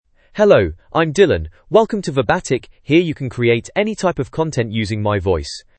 MaleEnglish (United Kingdom)
Dylan is a male AI voice for English (United Kingdom).
Voice sample
Dylan delivers clear pronunciation with authentic United Kingdom English intonation, making your content sound professionally produced.